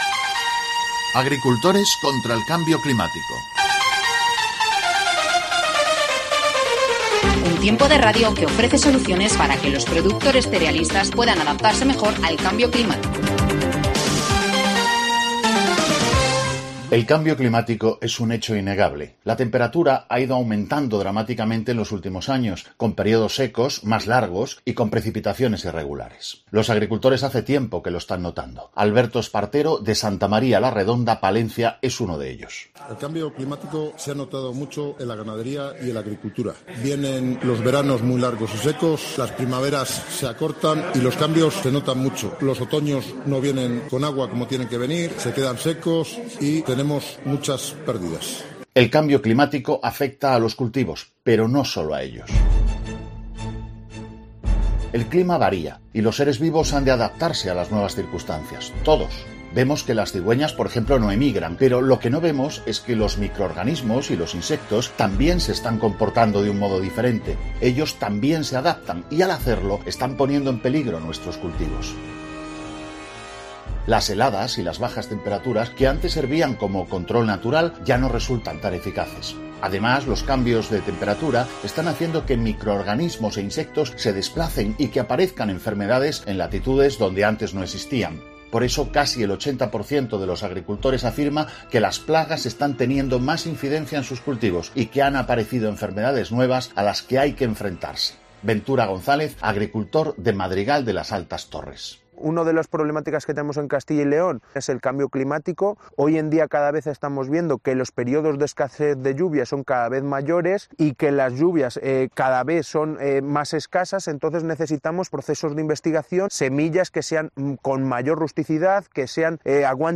30 07 21 INFORMATIVO MEDIODIA COPE SALAMANCA